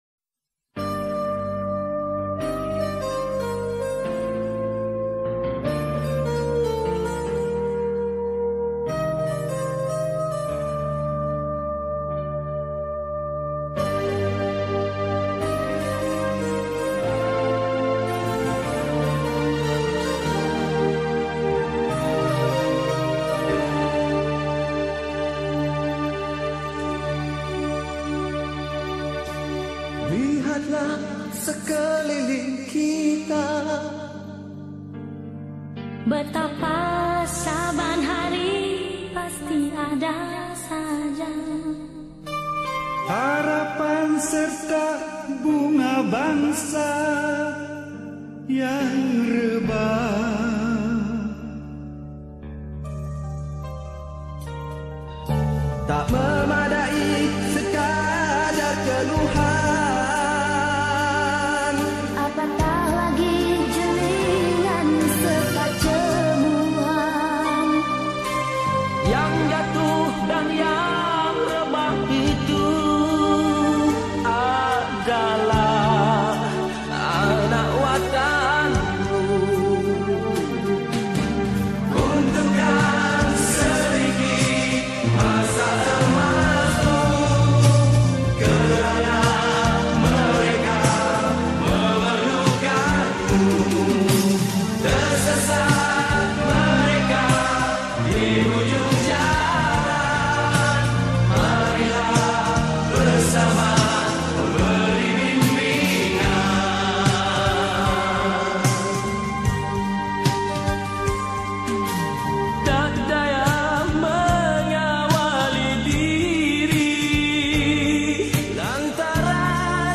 Lagu Patriotik Malaysia
Skor Angklung